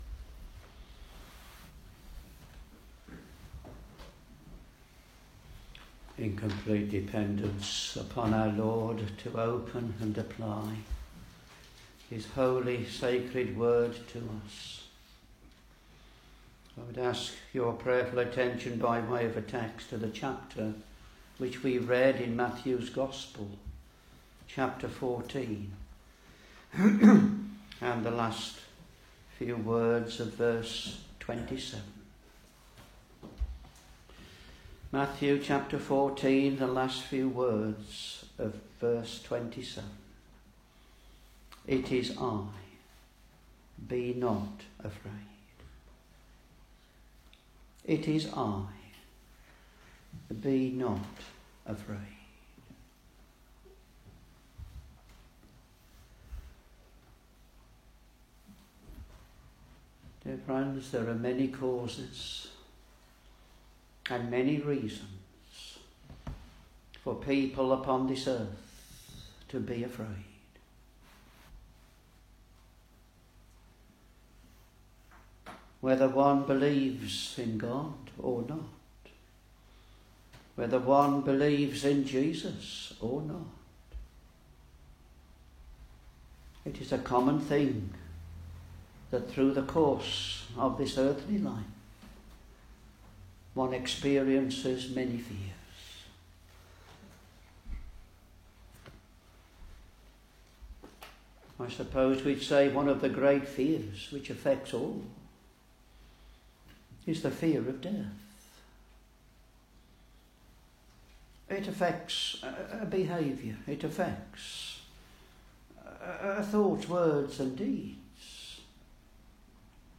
Sermons Matthew Ch.14 v.27 (last clause) it is I; be not afraid.